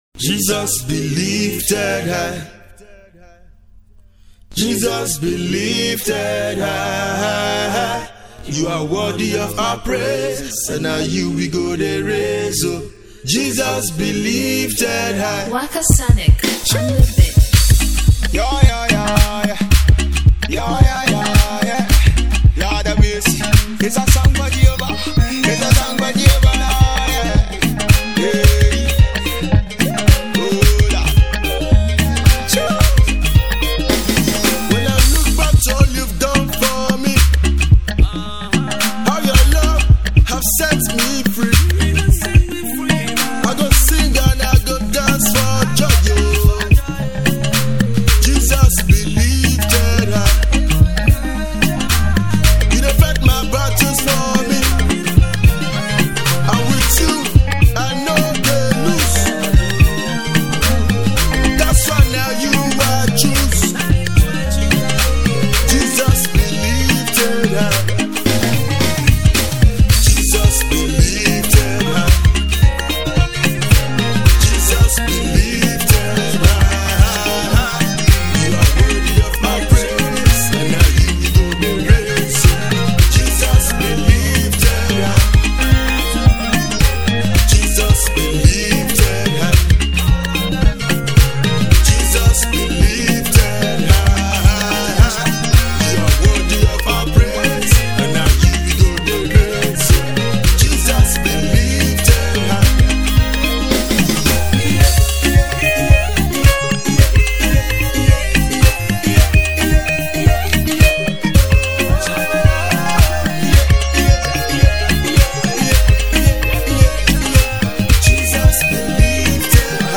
victory praise song